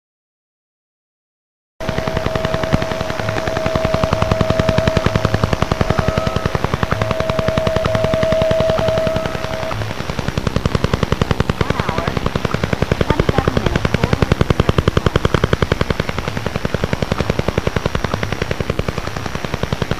File:Sound clip of sound transmitted by DUGA-3.mp3 - Signal Identification Wiki
Sound_clip_of_sound_transmitted_by_DUGA-3.mp3